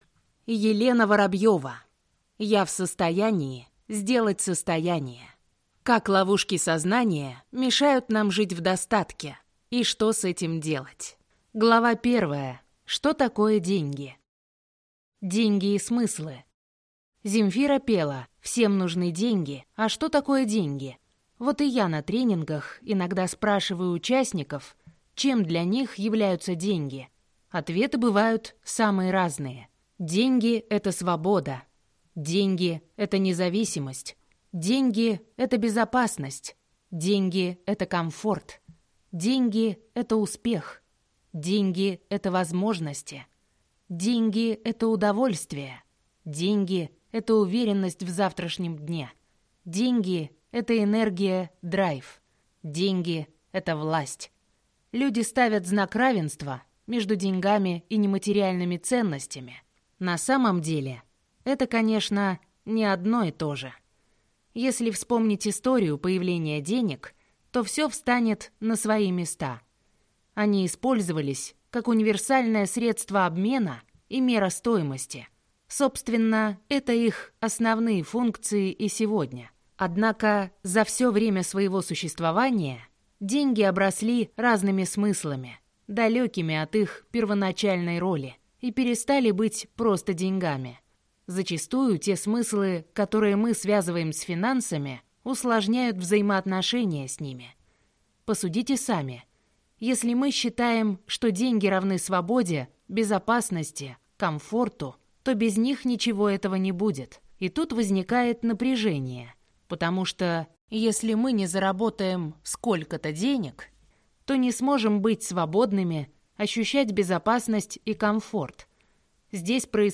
Аудиокнига Я в состоянии сделать состояние. Как ловушки сознания мешают нам жить в достатке, и что с этим делать | Библиотека аудиокниг